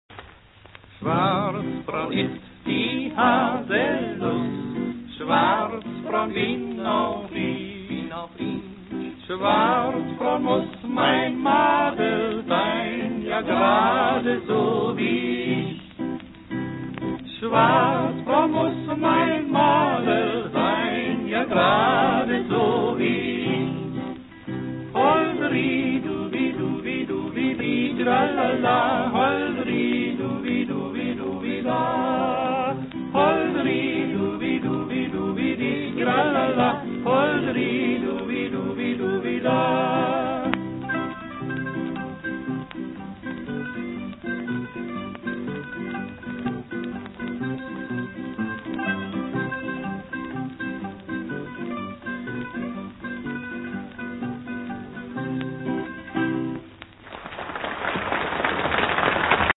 Послушайте её начало уже не в стиле походного марша, а в виде сентиментальной песенки (запись 30-х годов):